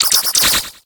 Cri de Crèmy dans Pokémon HOME.